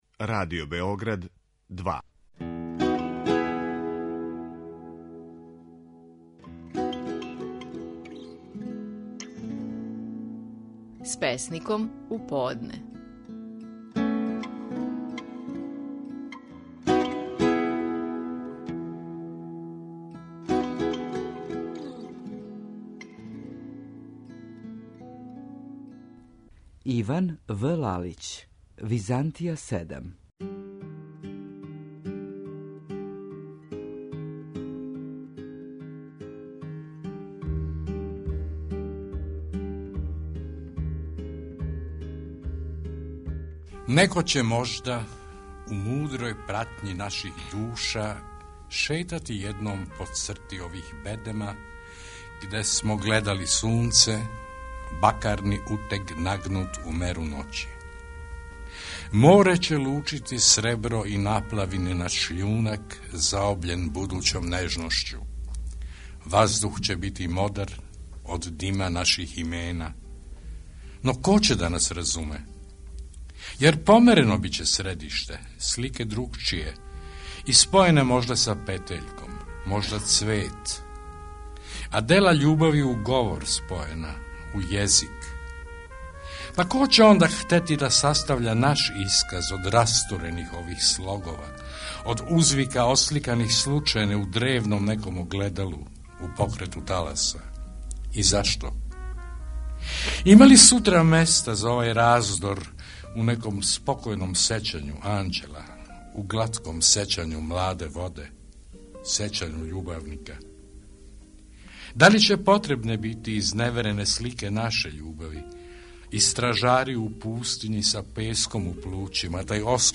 Стихови наших најпознатијих песника, у интерпретацији аутора.
Иван В. Лалић говори своју песму „Византија".